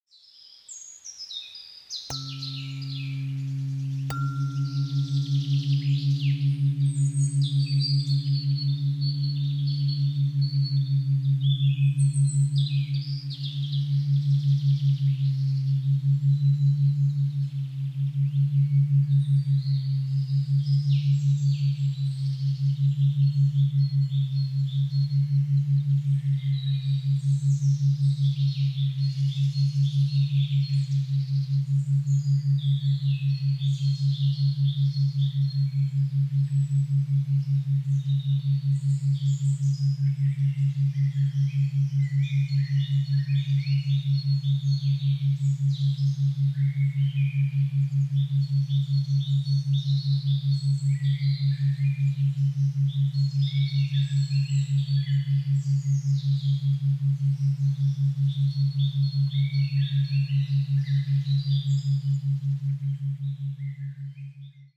Dźwięki Binauralne Theta 5,17 Hz – Podświadomość i Medytacja
Zanurz się w wewnętrzny spokój dzięki 1-minutowej próbce dźwięków binauralnych o częstotliwości 5,17 Hz – stworzonych na bazie naturalnych częstotliwości 136,1 Hz (OM Ziemia) i 141,27 Hz.
Probka-–-Dzwieki-Binauralne-Theta-517-Hz-–-Podswiadomosc-i-Medytacja.mp3